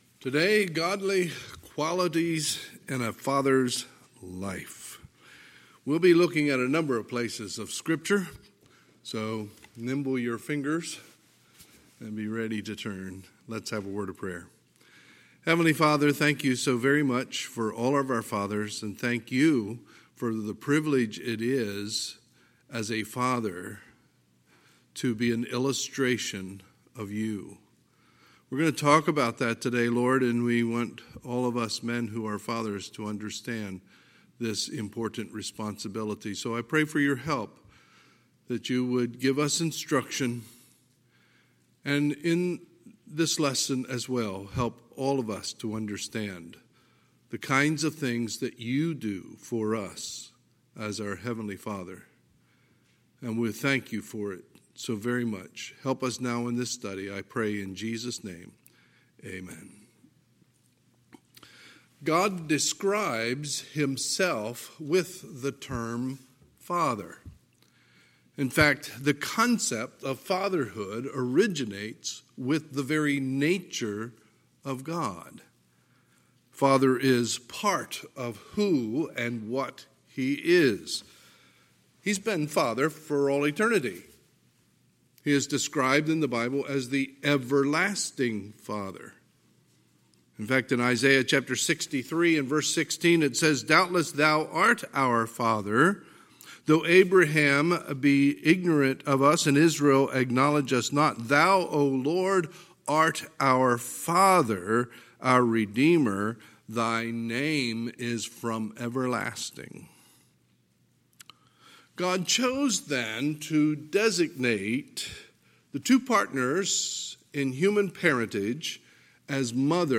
Sunday, June 21, 2020 – Sunday Morning Service
Sermons